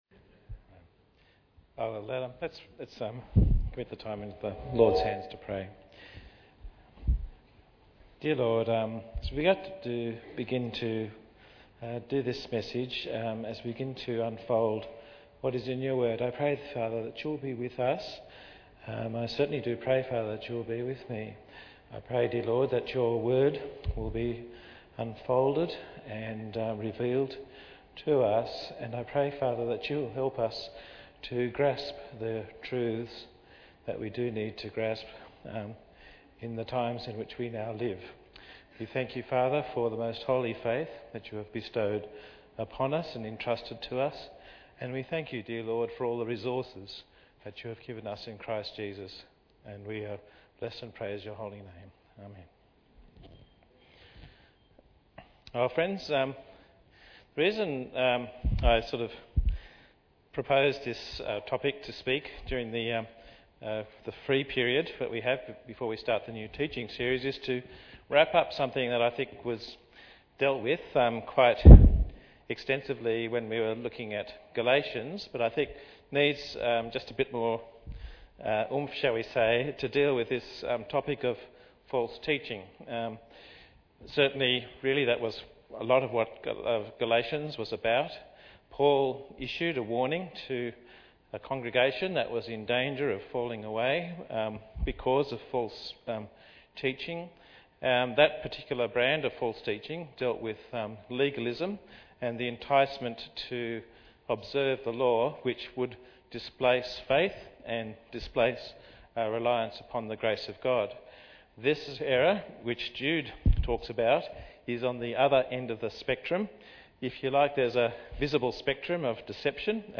Series: Guest Preachers